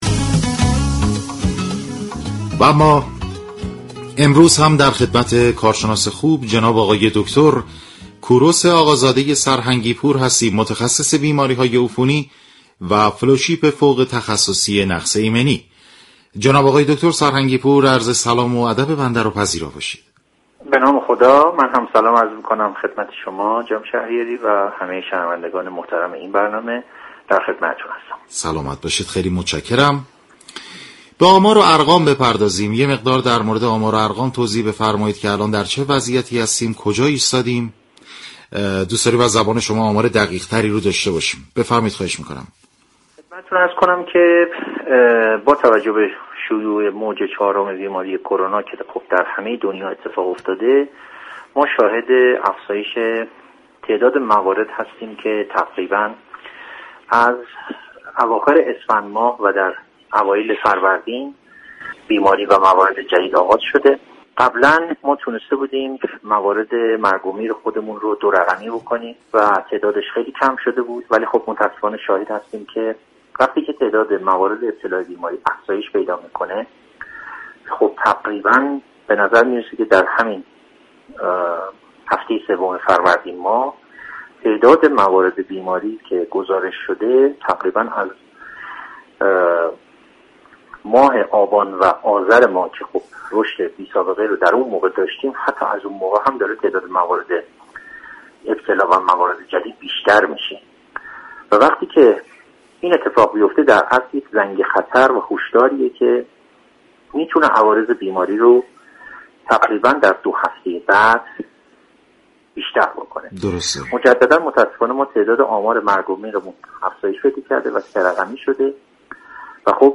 شما می توانید از طریق فایل صوتی پیوست شنونده ادامه این گفتگو باشید.